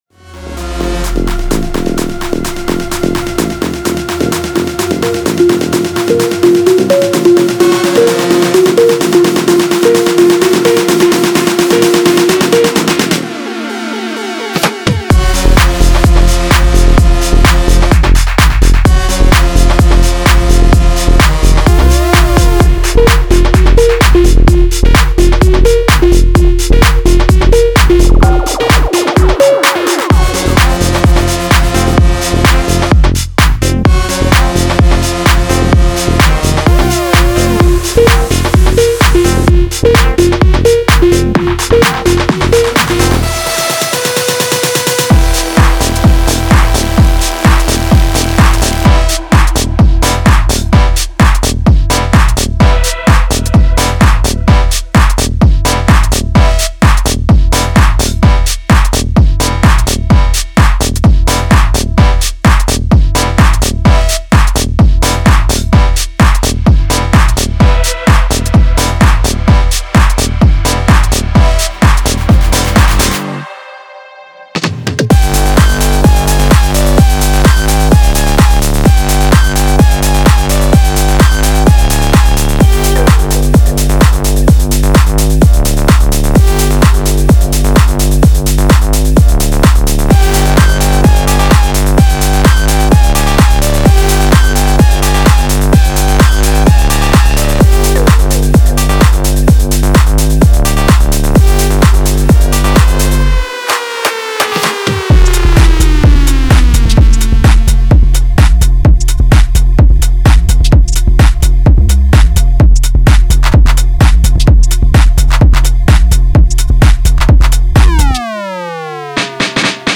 Genre:Tech House
ワンショットにはパンチの効いたキック、シャープなスネア、鋭いパーカッシブヒットが含まれ、リズムの微調整に役立ちます。
デモサウンドはコチラ↓
30 Full Drum Loops 128 Bpm